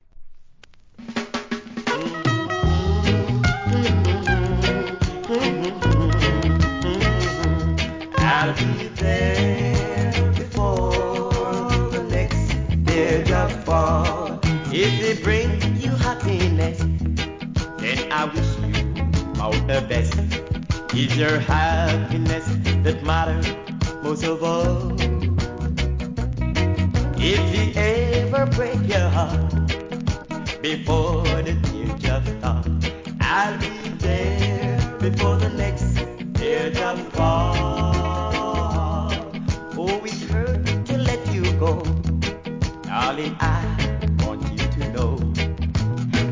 REGGAE
と優しく歌い上げるLOVE SONG!!